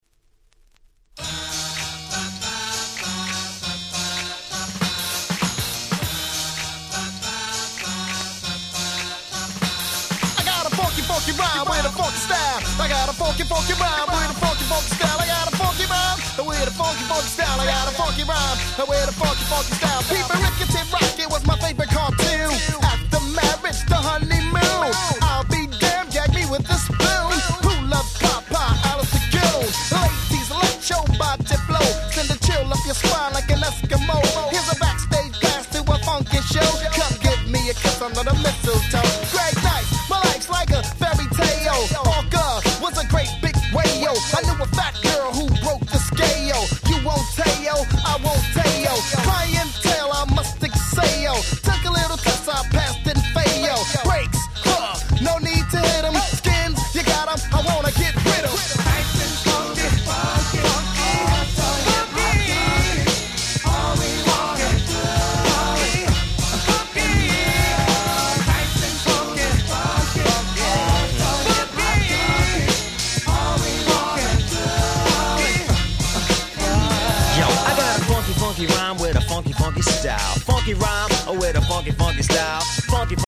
91' Super Hip Hop Classic !!
ナイスアンドスムース 90's Boom Bap ブーンバップ